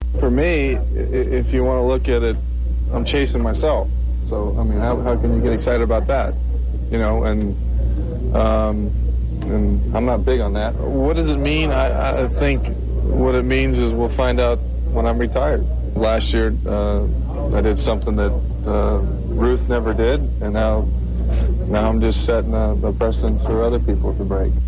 RA Audio: Interview on Home Run #50 - 22 AUG 1999